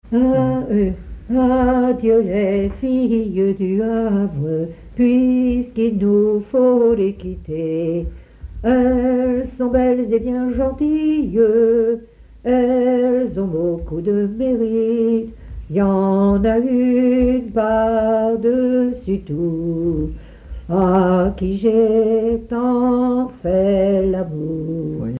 chant, chanson, chansonnette
Genre strophique
Pièce musicale inédite